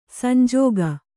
♪ sanjōga